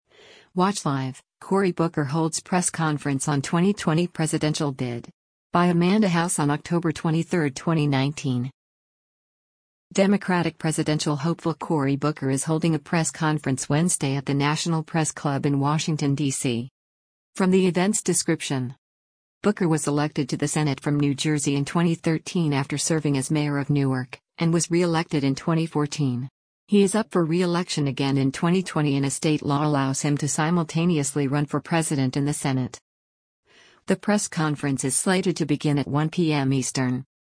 Democratic presidential hopeful Cory Booker is holding a press conference Wednesday at the National Press Club in Washington, DC.